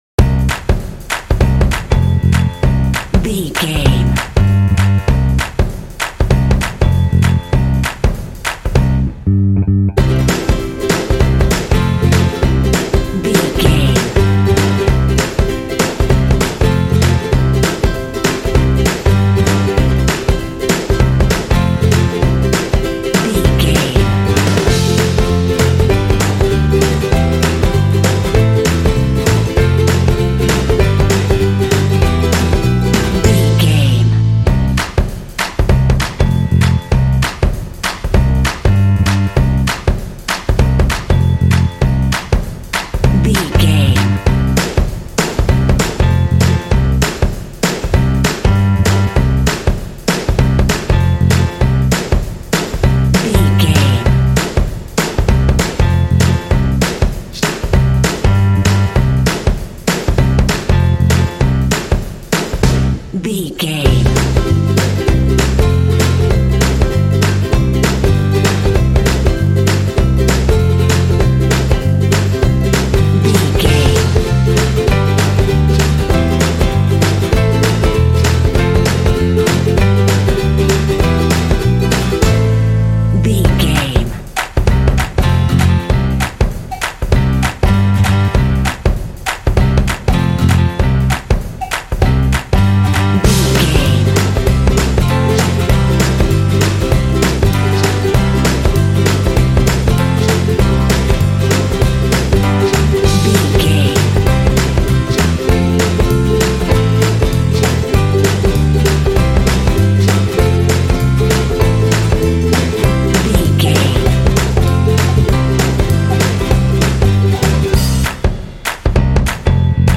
Ionian/Major
bouncy
bright
driving
energetic
festive
joyful
acoustic guitar
piano
bass guitar
drums
rock
contemporary underscore
alternative rock
indie